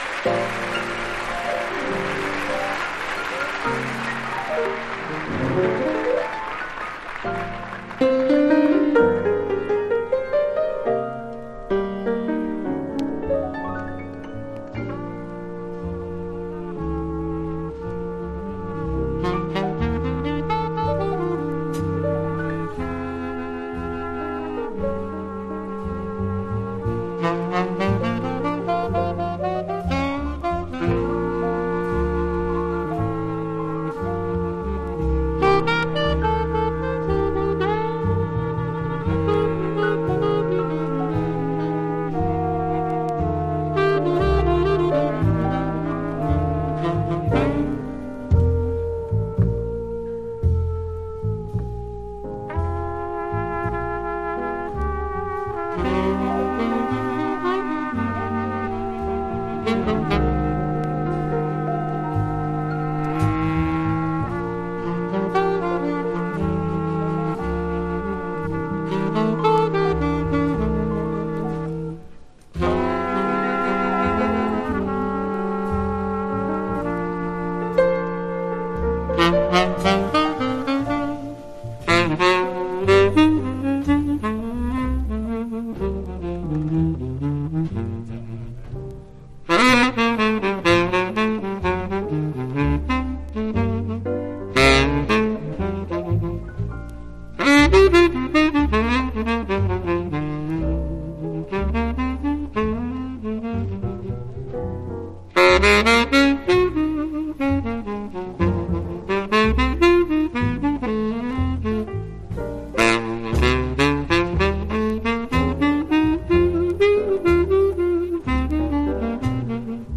ドイツでのLive